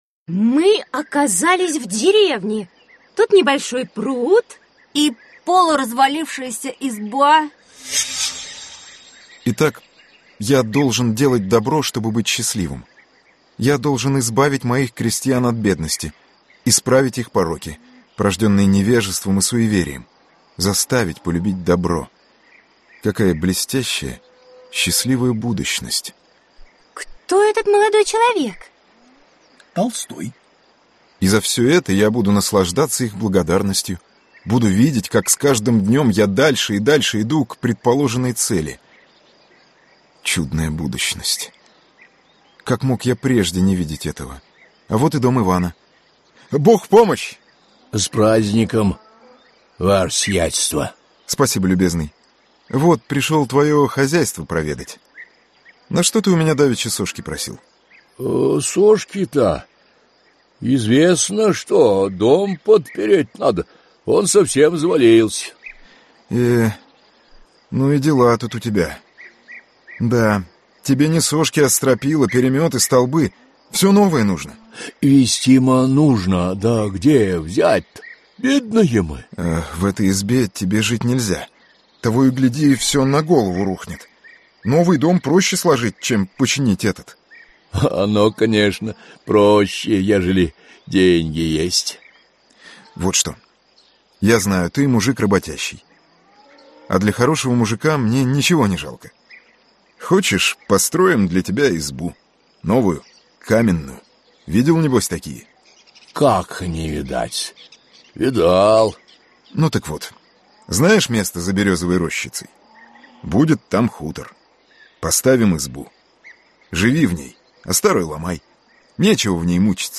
Аудиокнига Русские писатели: Л.Н.Толстой | Библиотека аудиокниг